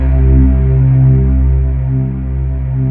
Index of /90_sSampleCDs/USB Soundscan vol.28 - Choir Acoustic & Synth [AKAI] 1CD/Partition D/24-THYLIVOX